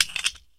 - Attach and detach weapon attachments has a sounds now.
action_detach_0.ogg